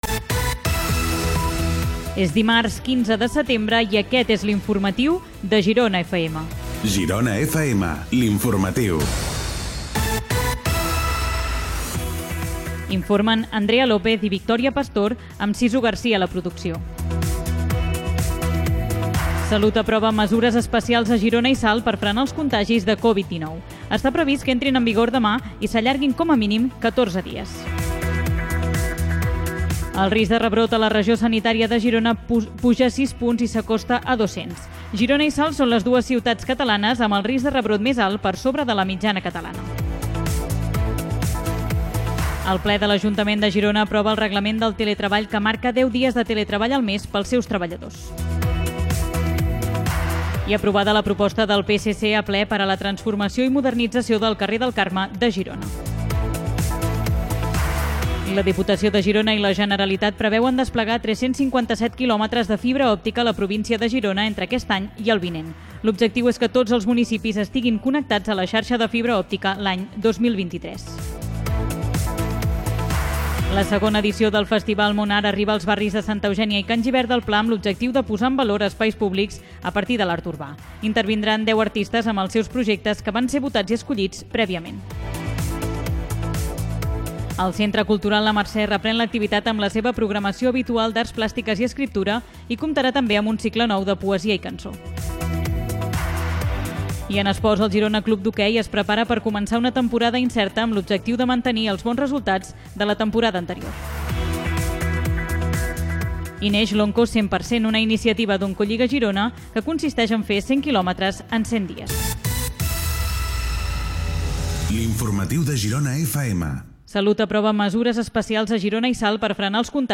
Data, careta del programa, sumari, indicatiu del programa, mesures per combatre la Covid-19.
Informatiu